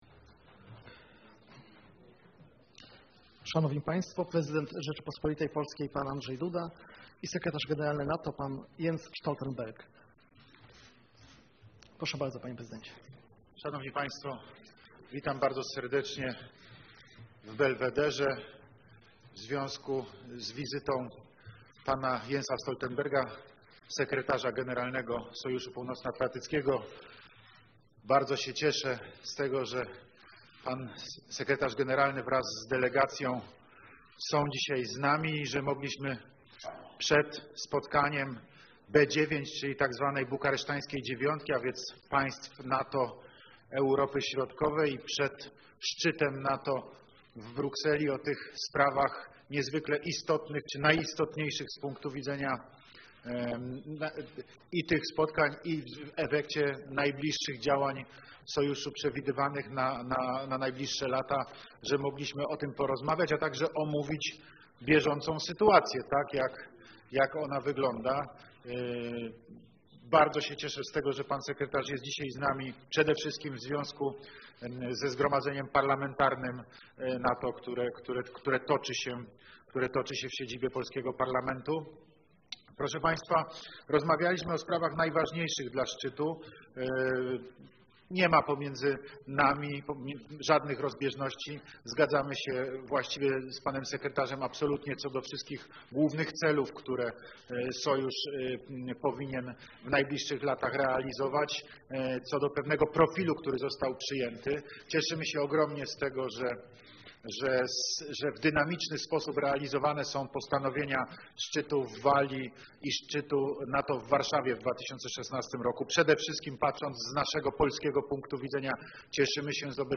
Joint press conference
by NATO Secretary General Jens Stoltenberg and the President of Poland, Andrzej Duda